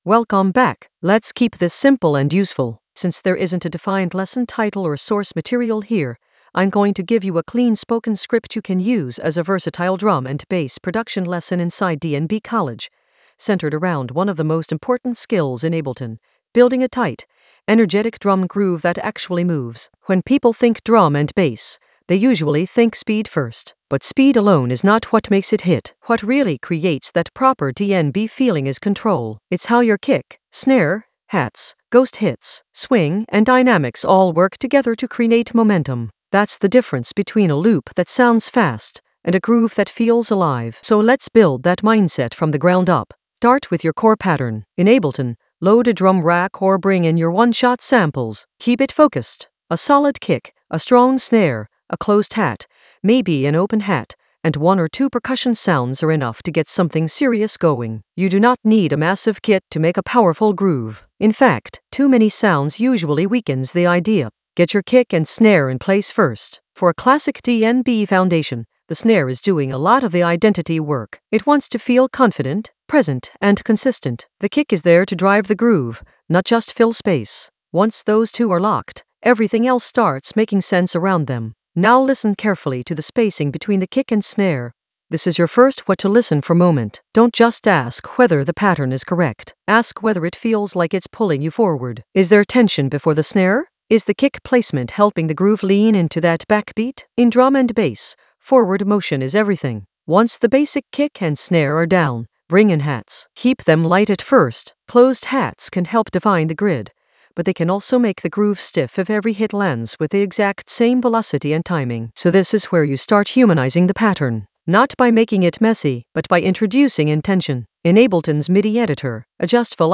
An AI-generated beginner Ableton lesson focused on DJ Sy energy: carve a fast-mix transition in Ableton Live 12 for rave-ready drum and bass sets in the DJ Tools area of drum and bass production.
Narrated lesson audio
The voice track includes the tutorial plus extra teacher commentary.